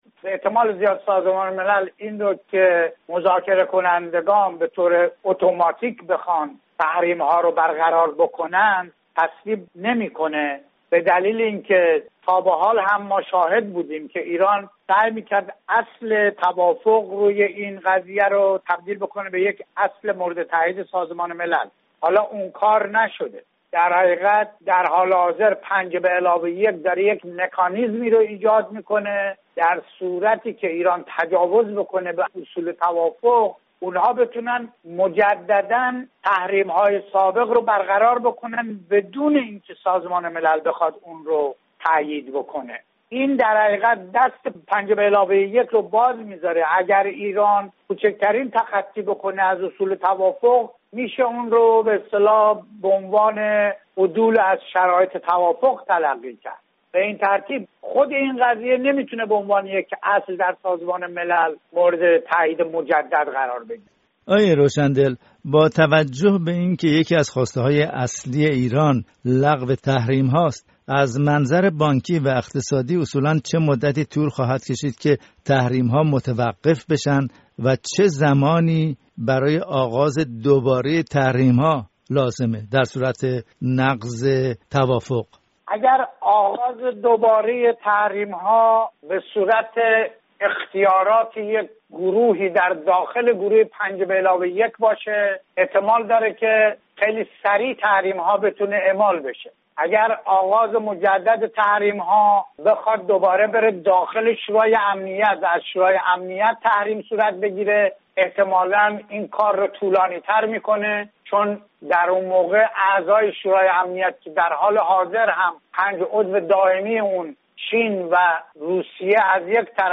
گفتگوی